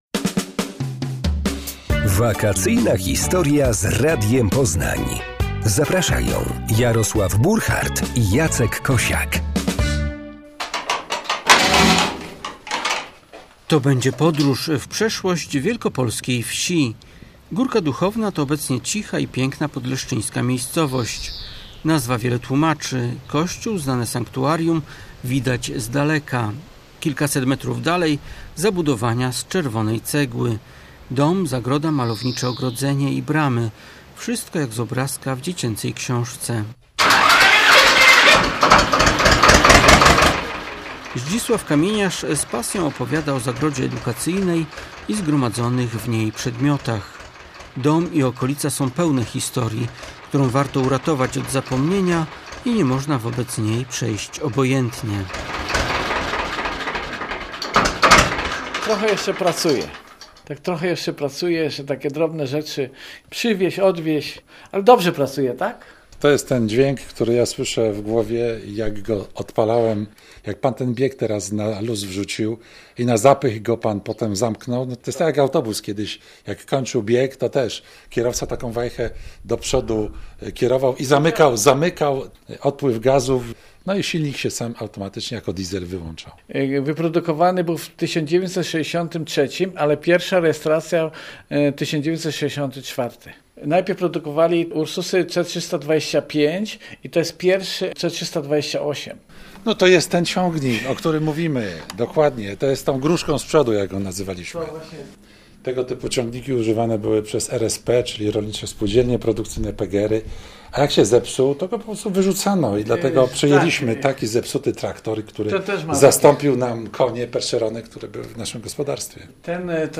Gospodarz z pasją opowiada o zagrodzie edukacyjnej i zgromadzonych w niej przedmiotach. Dom i okolica są pełne historii, którą warto uratować od zapomnienia i nie można wobec niej przejść obojętnie.